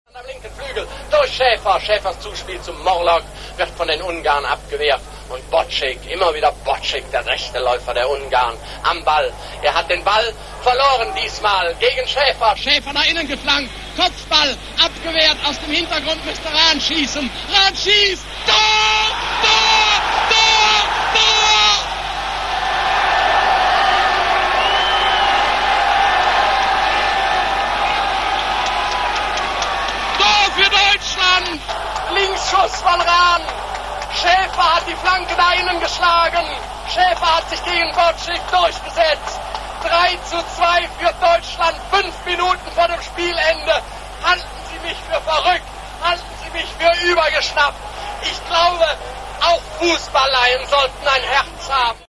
Die legendäre Reportage von Herbert Zimmermann.